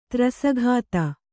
♪ trasa ghāta